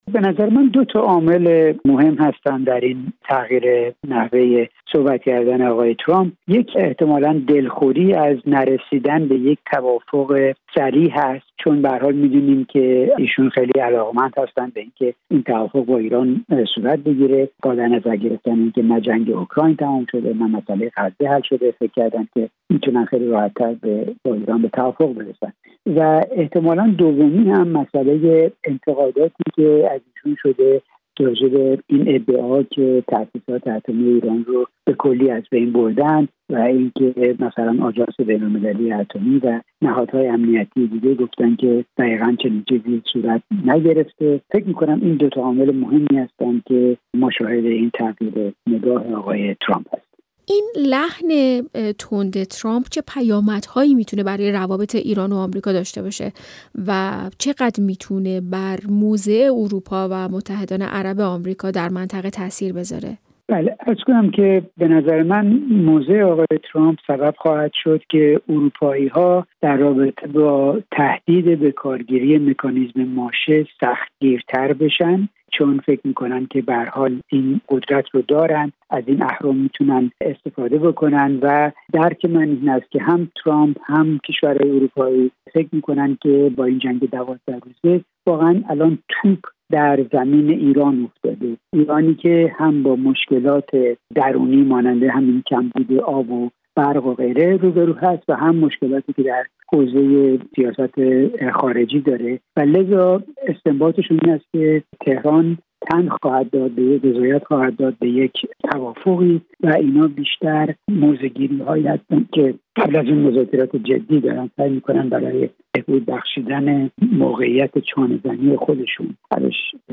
برنامه‌های رادیویی